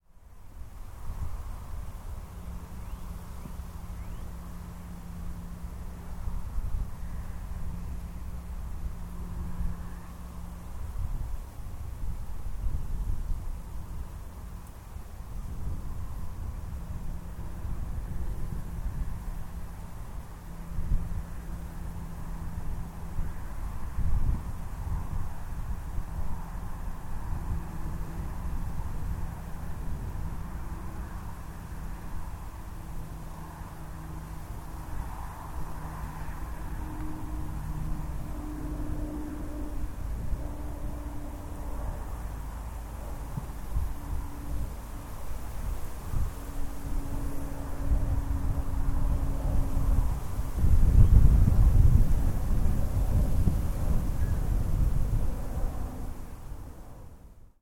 A recording taken at the site of the memorial for the Lancaster which came down in Bicker Fen in 1944.